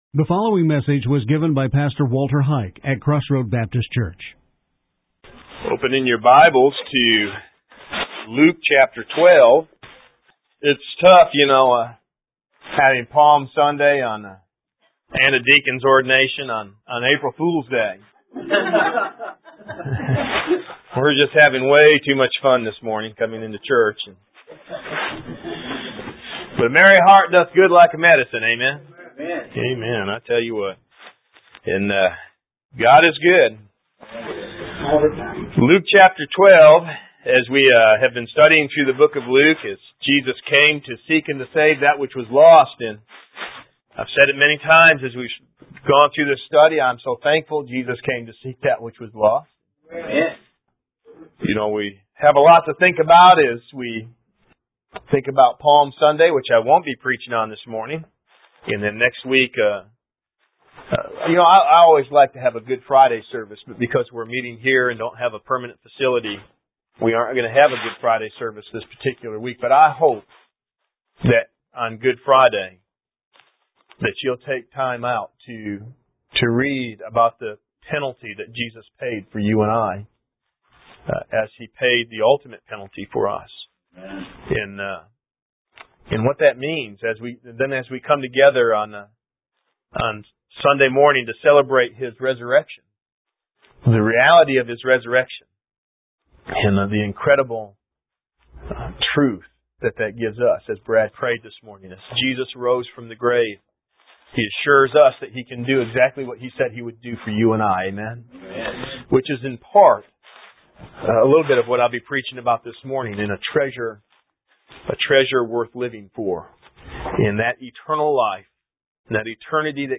Outline and Audio Sermons